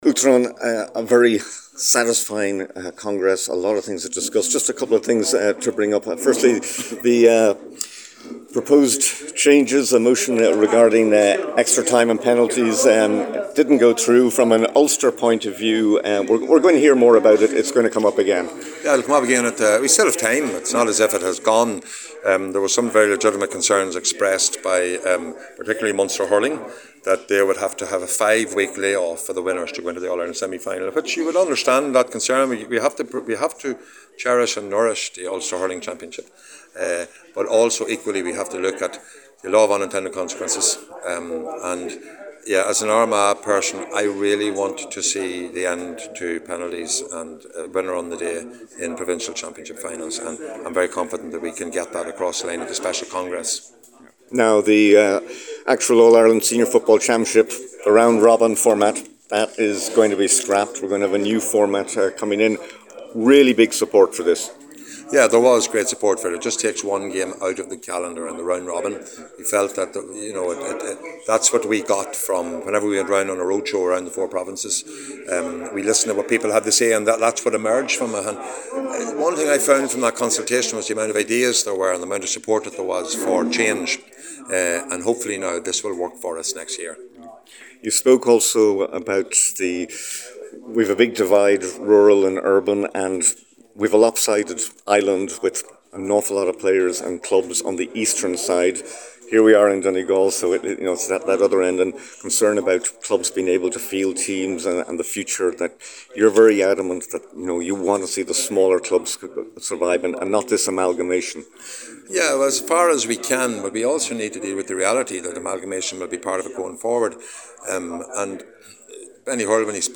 at the Abbey Hotel this afternoon…